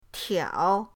tiao3.mp3